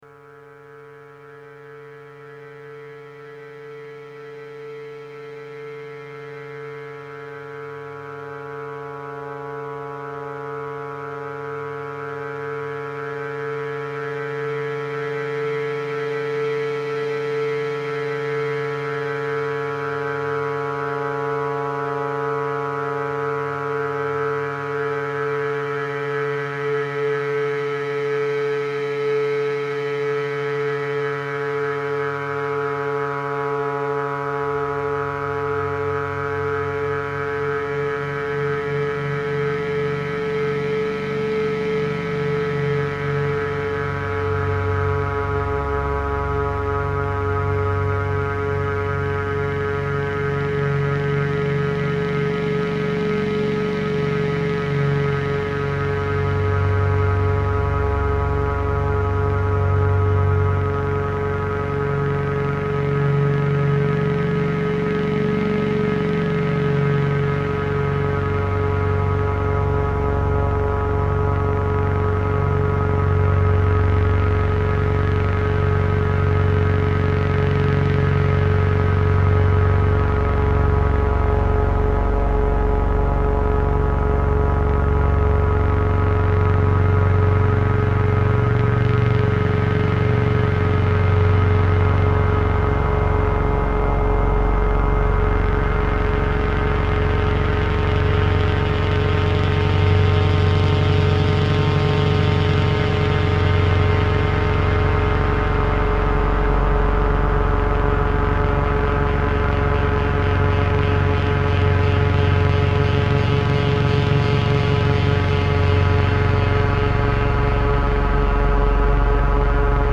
70s Synth, 90s reverb.